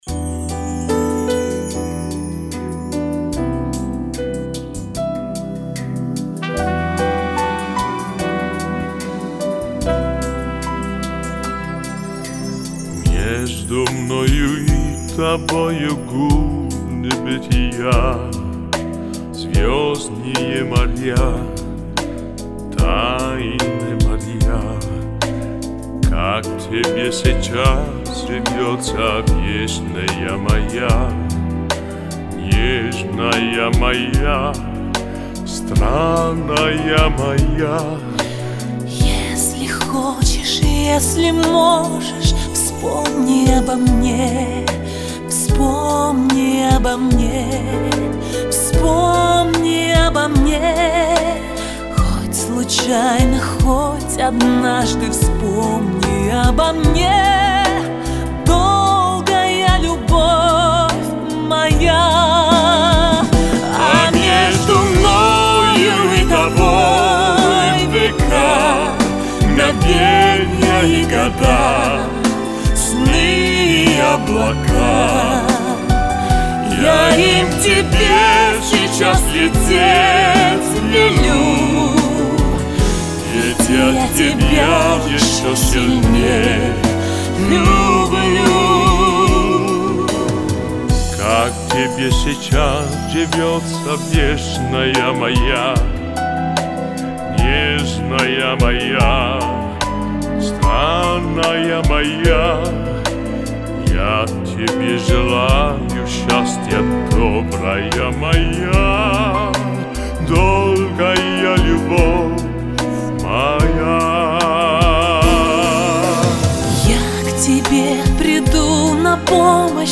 Категория: Грустные песни